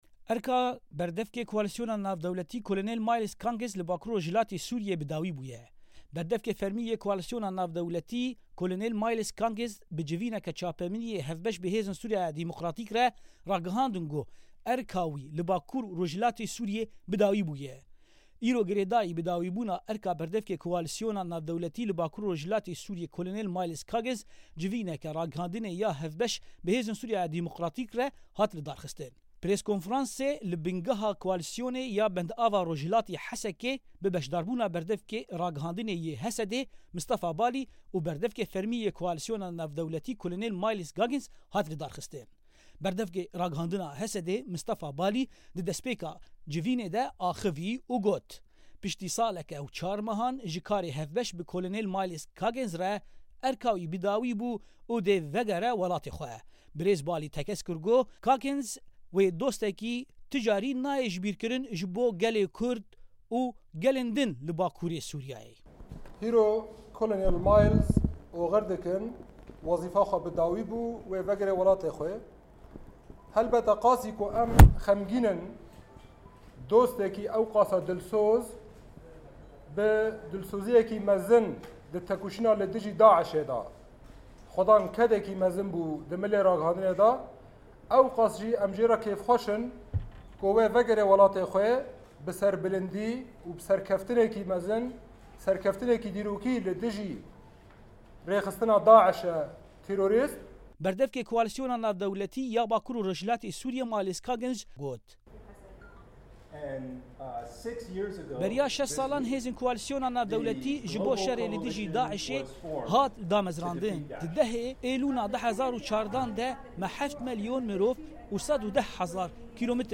Preskonferansa Miles Caggins û HSD ê
Di dawîya axaftina xwe de colonel Myles Caggins bi rengekî pirr hestîyar xatir ji xelkên rojava û bakûrê sûrîyê xwest , û ew girîya dema xatir ji hevkarên xwe yên HSD'ê xwest.